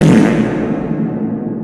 Play, download and share f4rt original sound button!!!!
f4rt.mp3